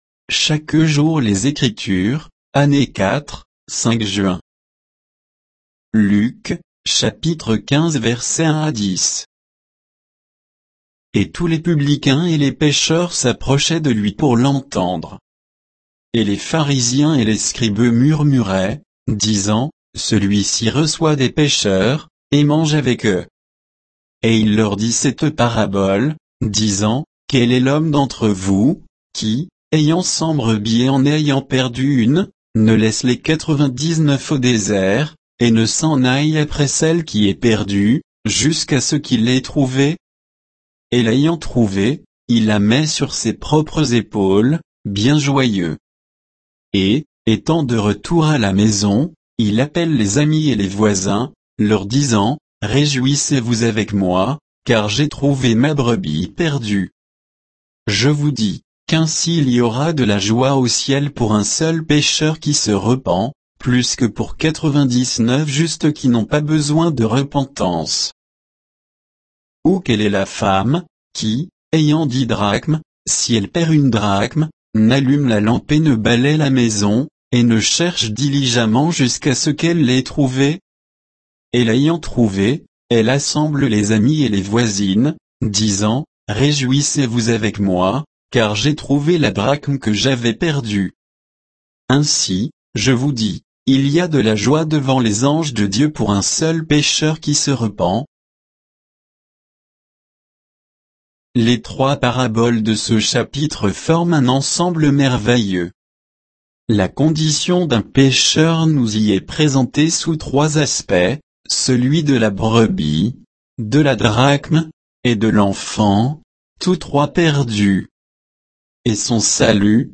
Méditation quoditienne de Chaque jour les Écritures sur Luc 15